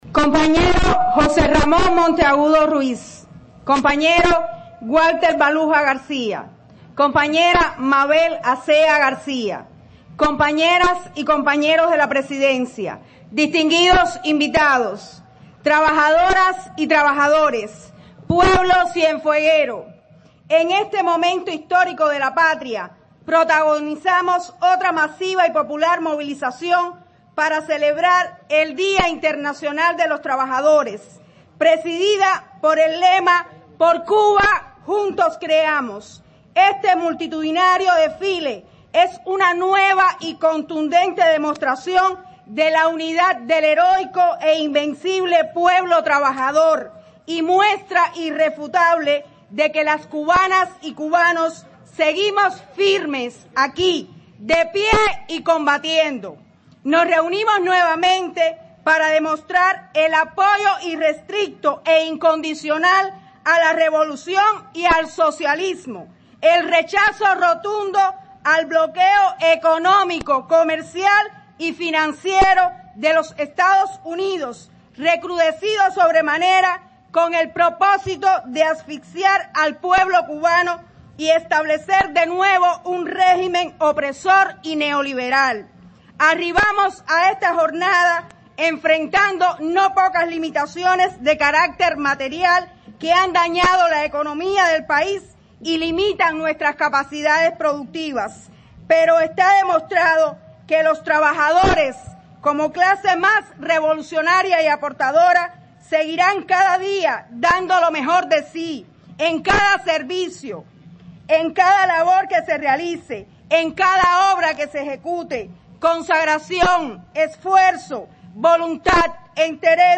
expresó en su intervención ante la multitud reunida que este jueves recordamos los 25 años del concepto de Revolución